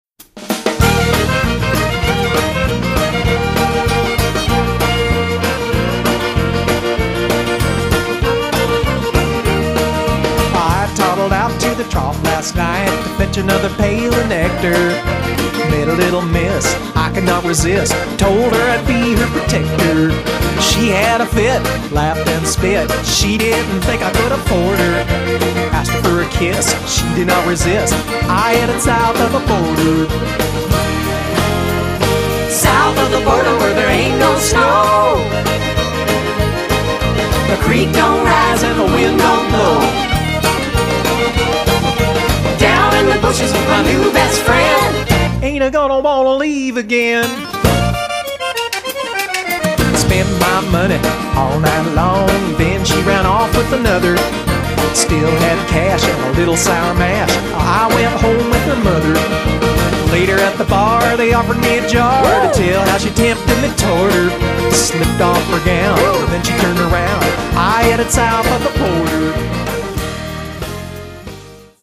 accordion, piano, B-3, vocals
drums, percussion
background vocals
bongos, congas, percussion
violin